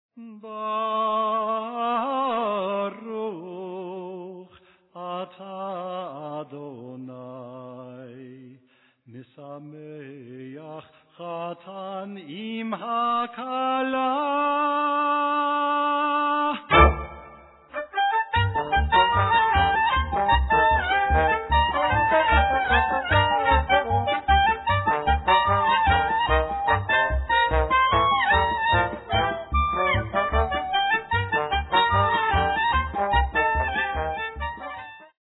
early music group